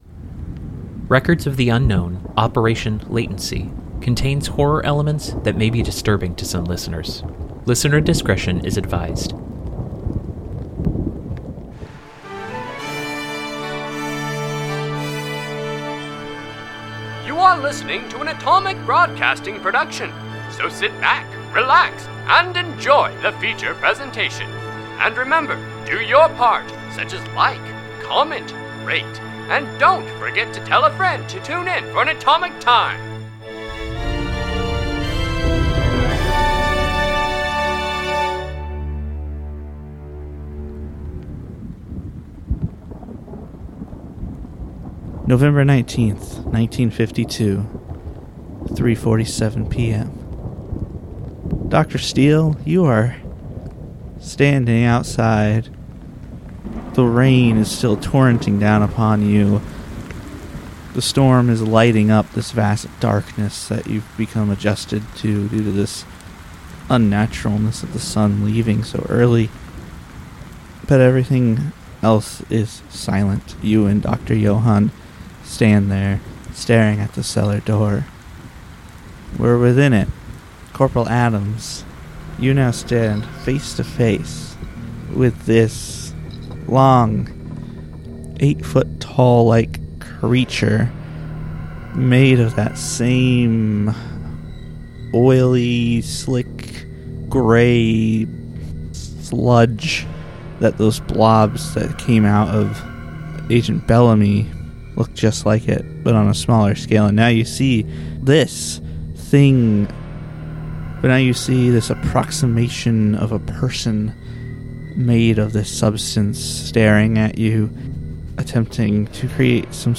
Records of the Unknown is an unscripted improvisational podcast based on the game Delta Green by Arc Dream Publishing.